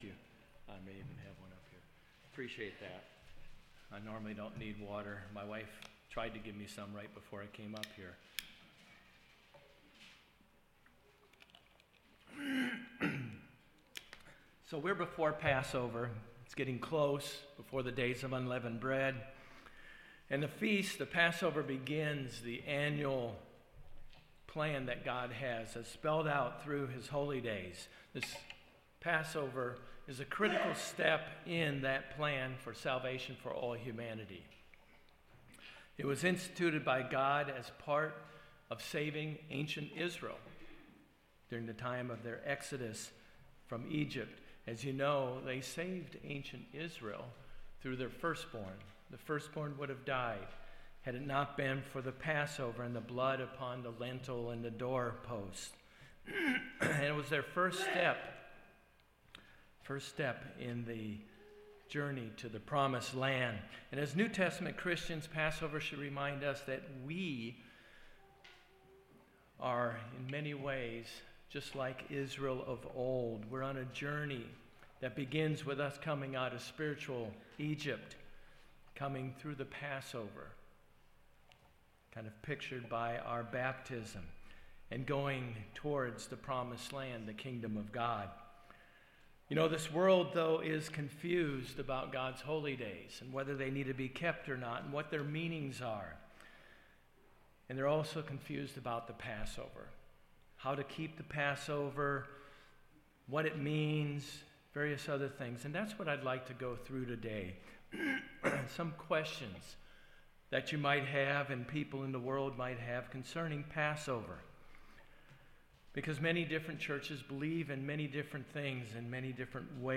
Sermons
Given in Jacksonville, FL